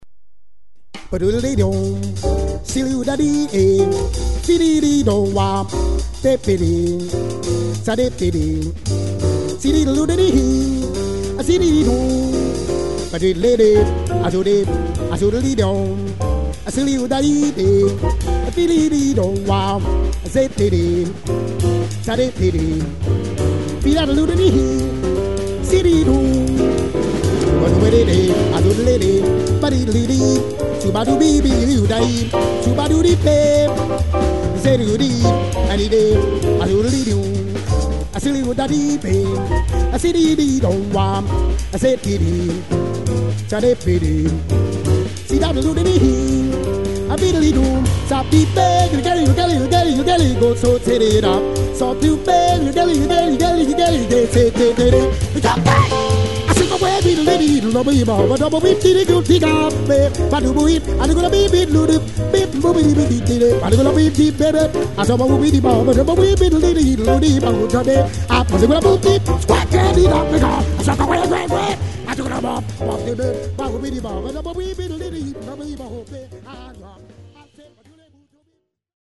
vocals
piano, Fender Roads, Synthesizer
acoustic, electric bass
drums